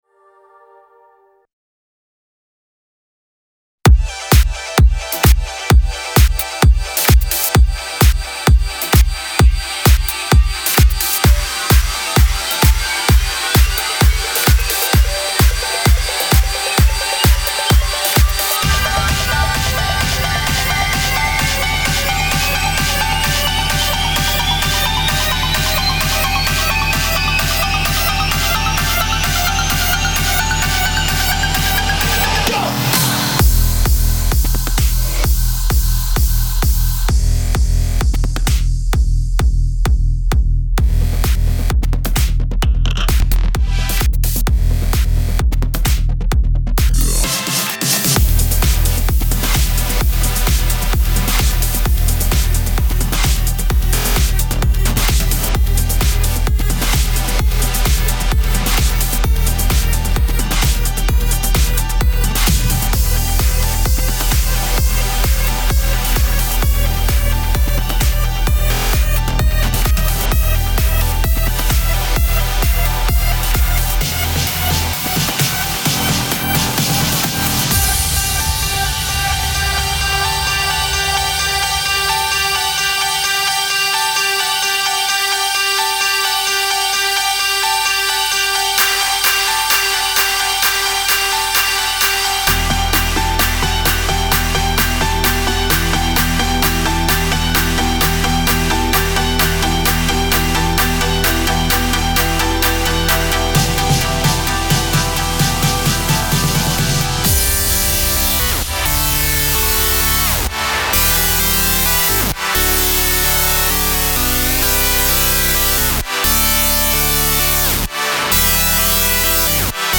Жанр: Electronica-Trance